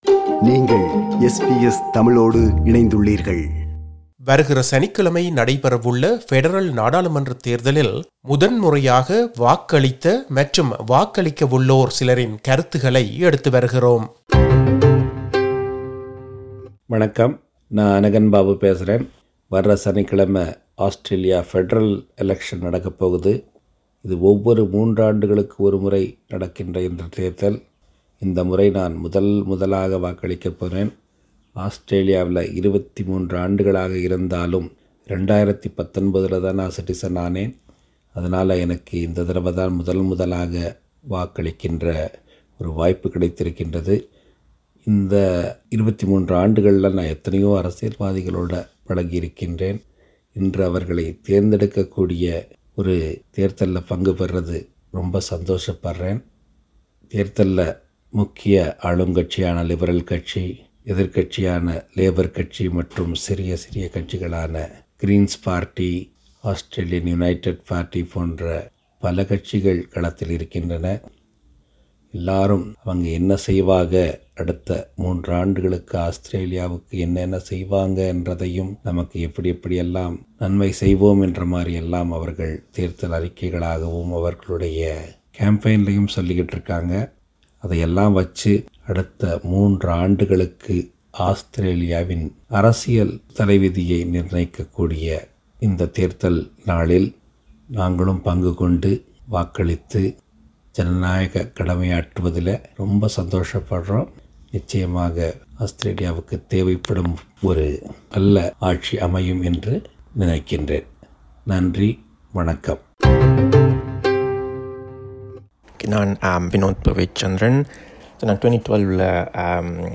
elex_2022_voxpop_web.mp3